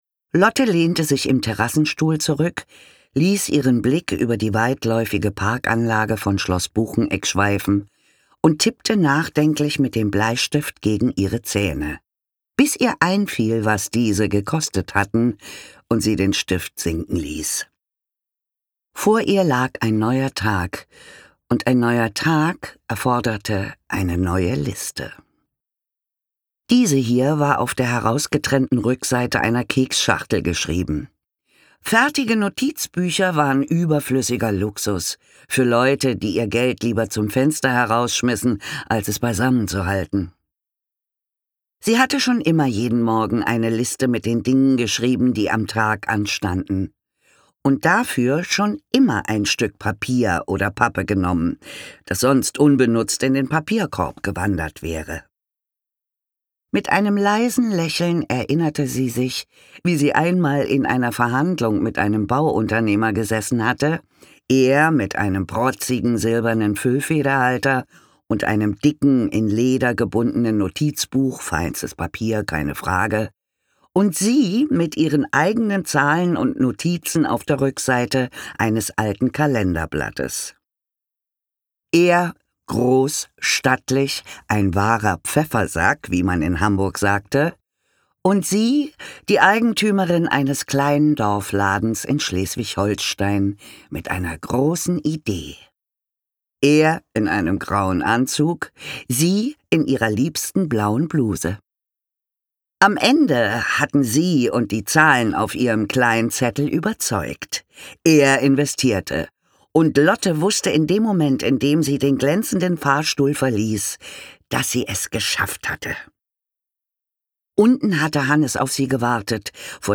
Manon Straché liest
Sprecherin: Manon Straché
ungekürzte Lesung